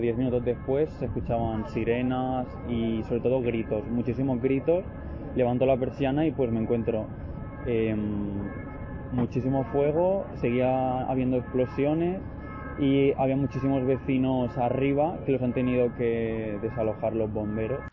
vecino de la zona, explica como ha sido el momento de la explosión